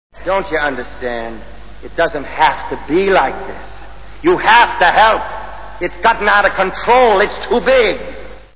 Capricorn One Movie Sound Bites